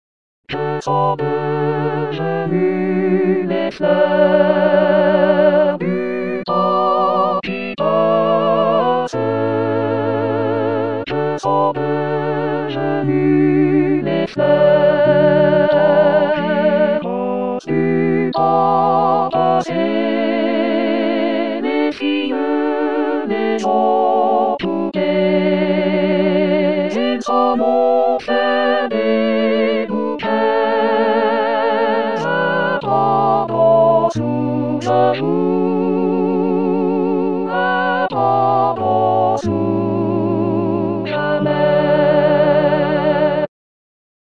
Basses...